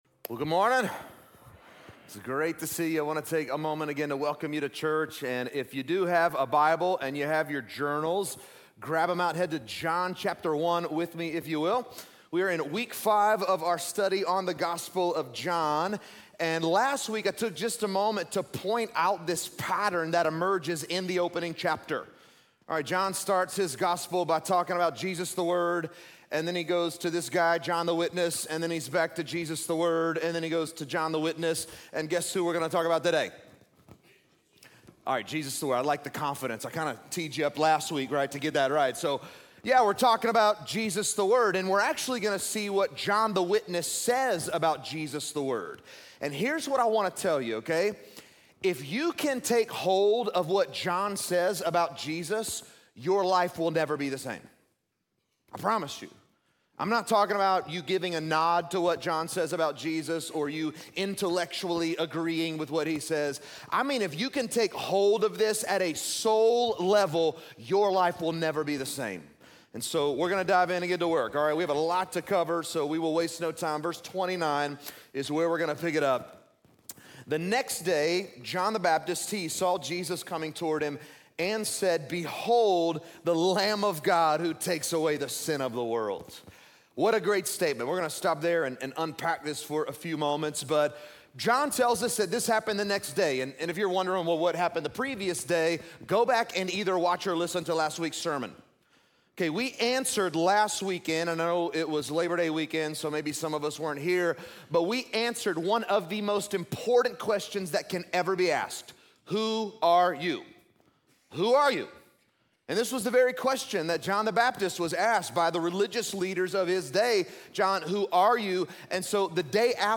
This message is from our "The Gospel of John" series: "Behold the Lamb" The Gospel of John was written by the Apostle John, one of Jesus’ twelve disciples and a member of his inner circle.